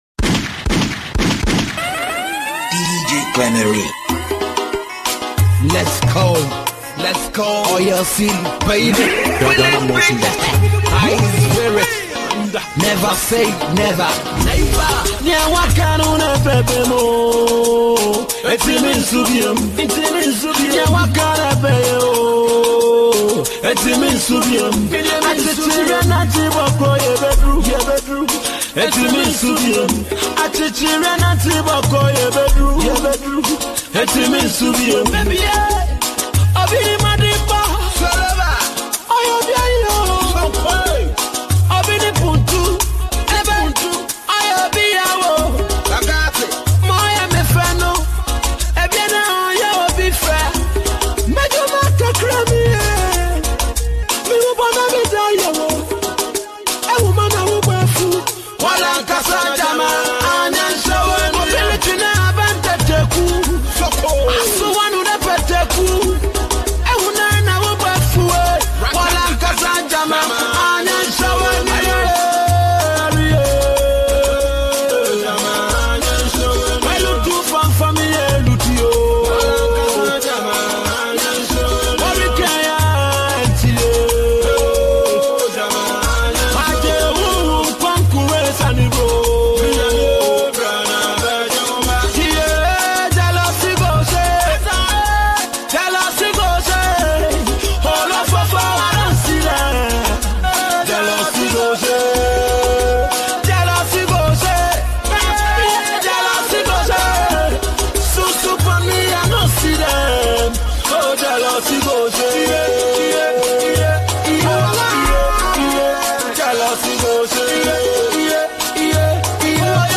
vibrant sounds of Ghana
Genre: Mixtape